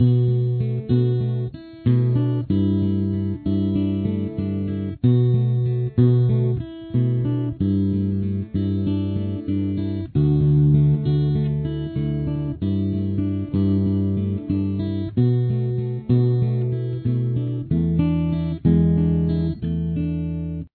D, G, D, G, B, E
The audio is accentuated for timing purposes.
Pre-Chorus